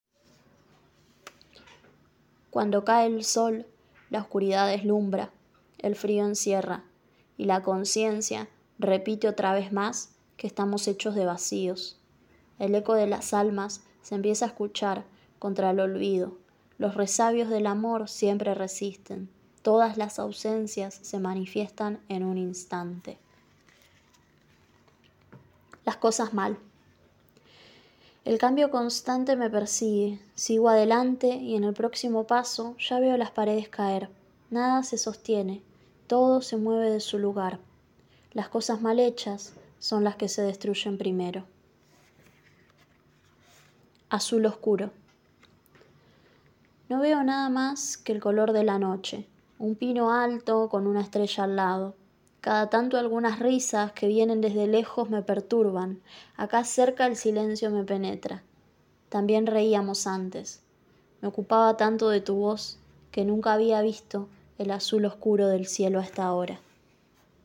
Premier de tres poemas en la voz de su autora: